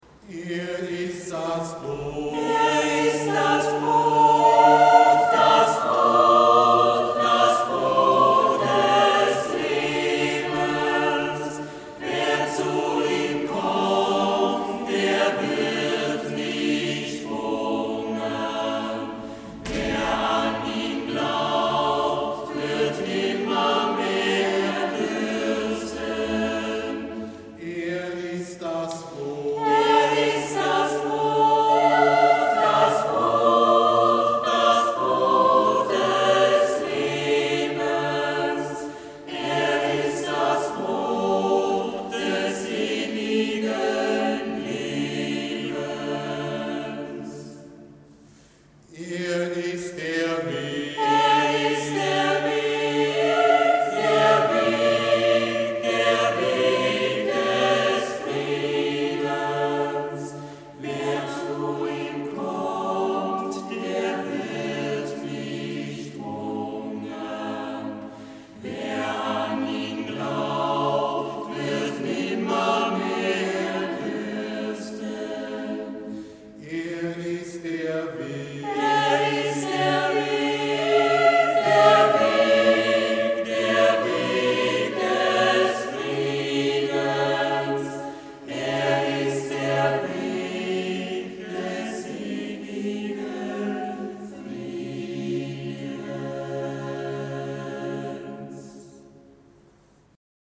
begleitete der Projektchor das Hochamt der Auferstehungsfeier Ostern 2012
L. Maierhofer